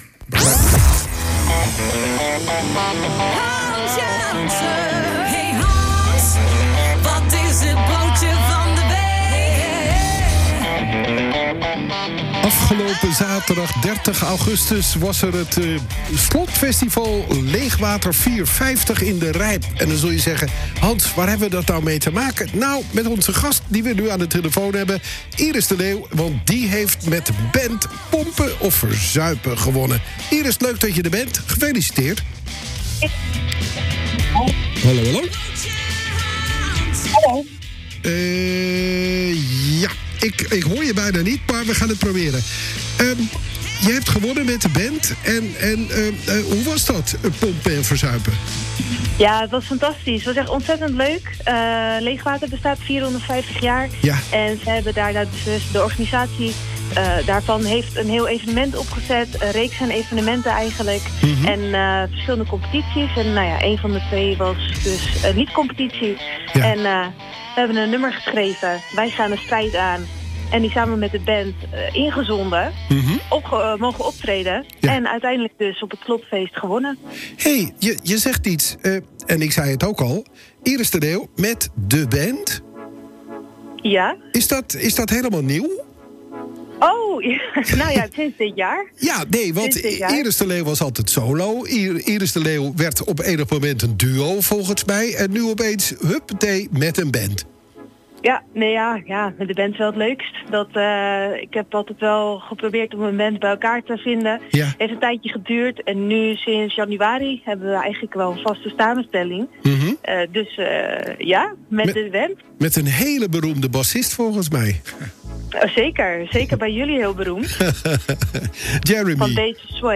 Wij belden met haar..